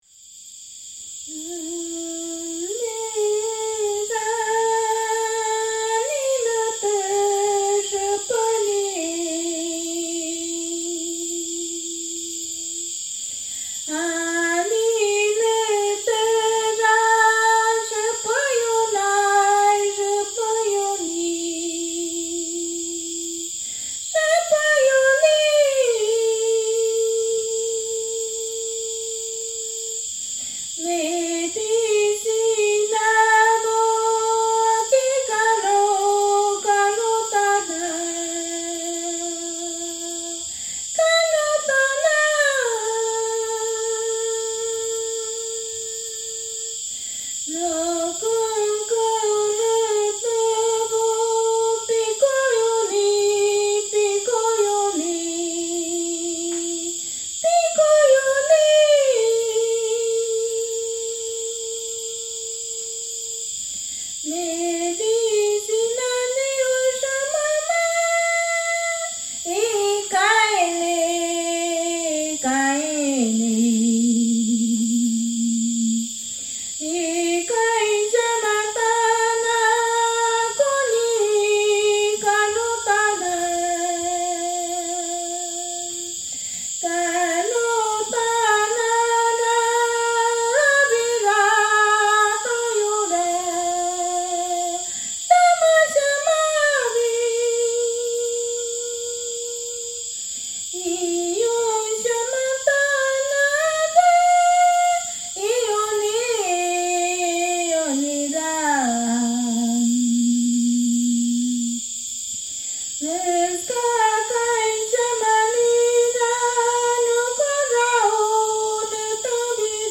Headliner Embed Embed code See more options Share Facebook X Subscribe Living with an isolated Shipibo indigenous family in the Peruvian Amazon
ícaro
curandera